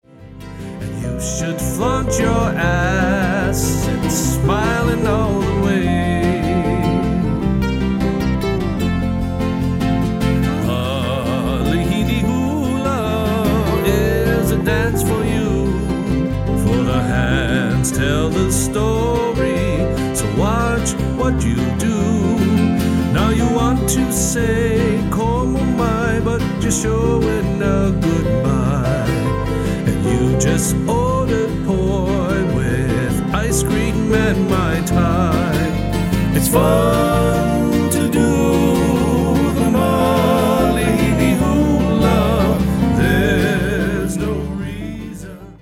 • Genre: Contemporary Hawaiian.